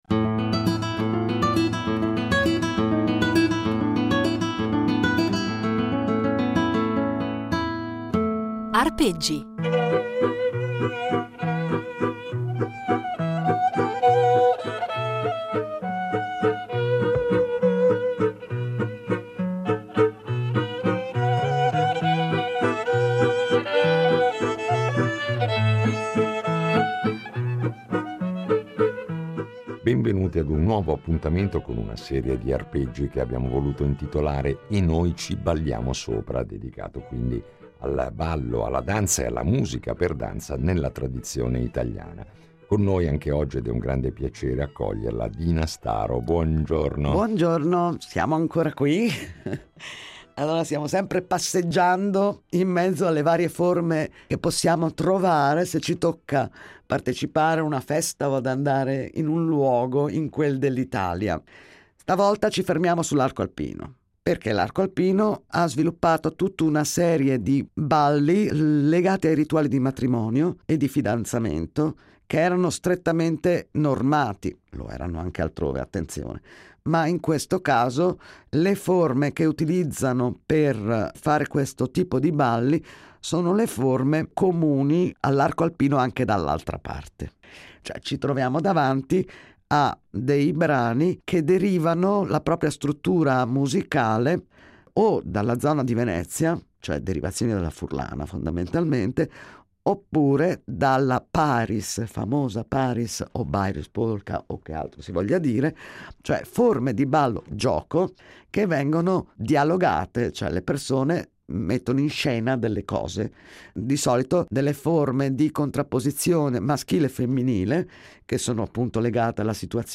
Un itinerario sonoro ricco di materiale inedito, registrato sul campo e negli anni da lei stessa, e illuminato da esempi che ci propone dal vivo, con la sua voce e il suo violino e accompagnata dalle percussioni di